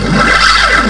organ-samples